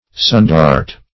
sundart - definition of sundart - synonyms, pronunciation, spelling from Free Dictionary Search Result for " sundart" : The Collaborative International Dictionary of English v.0.48: Sundart \Sun"dart`\, n. Sunbeam.